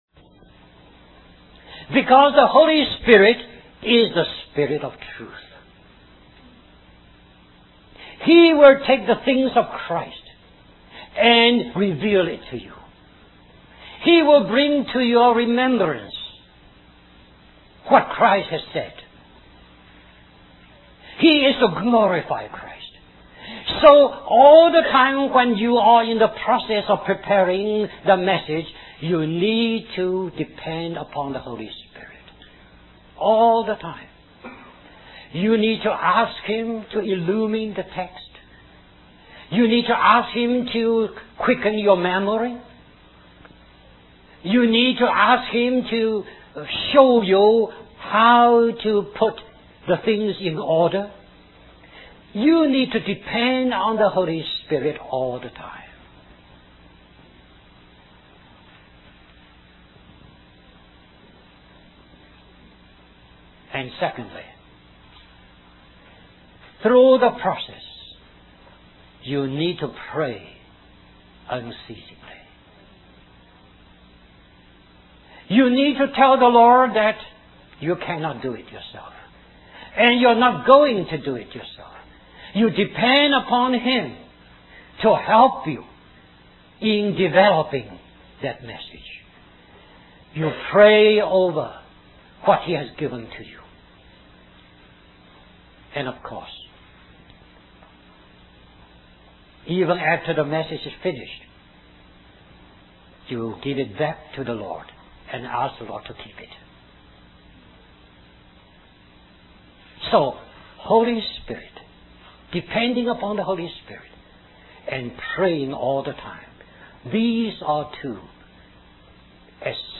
A collection of Christ focused messages published by the Christian Testimony Ministry in Richmond, VA.
1990 Florida Leadership Conference Stream or download mp3 Summary The beginning portion of this tape may be found at this link .